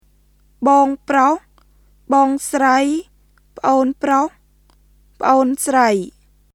[ボーン（プロホ／スライ）、プオーン（プロホ／スライ）　 bɔːŋ(proh / srəi),　pʔoːn (proh / srəi)]